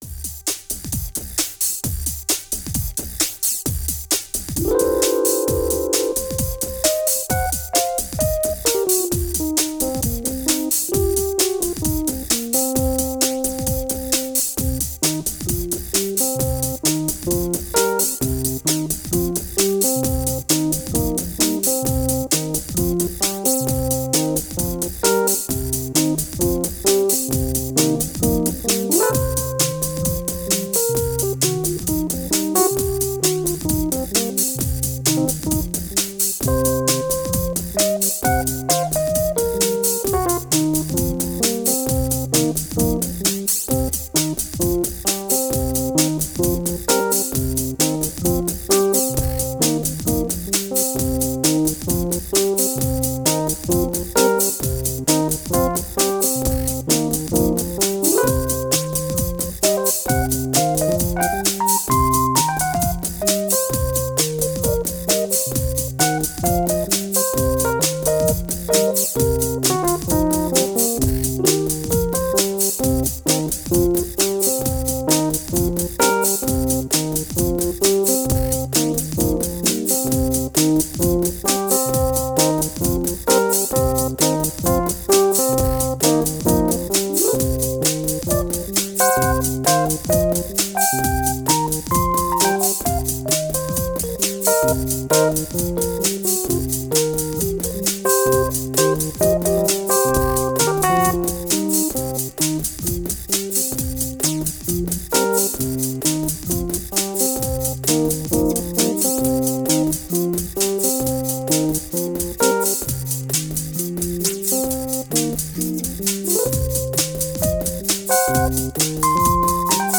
Tempo: 132 bpm / Datum: 09.11.2017